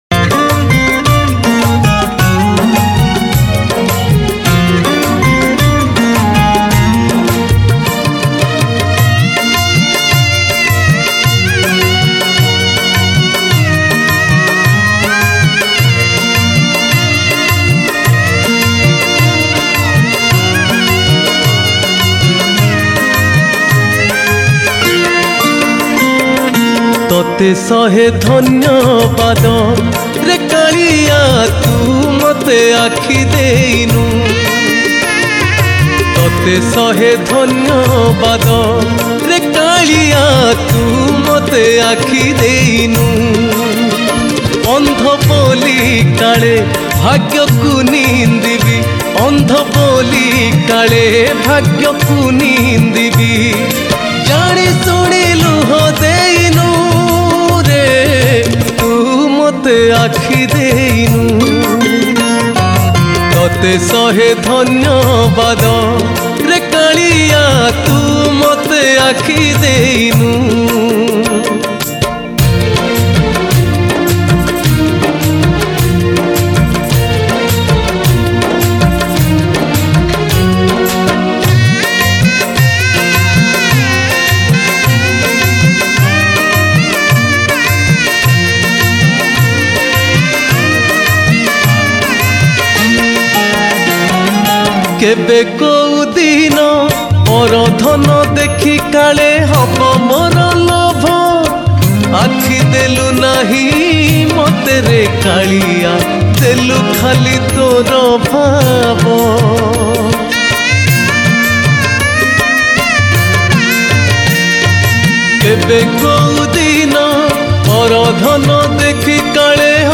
Odia Bhajan